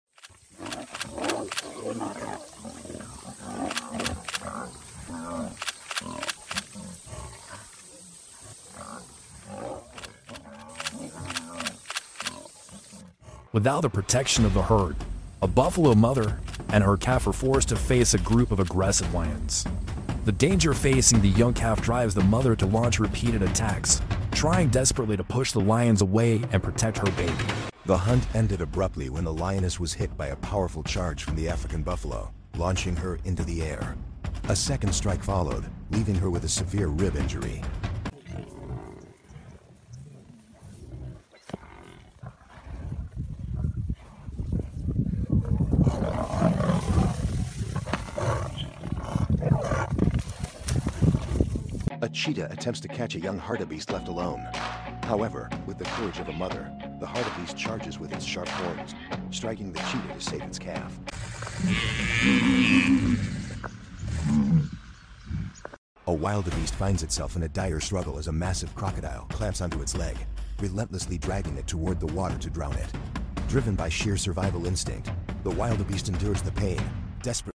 buffalo hit loin fighting video sound effects free download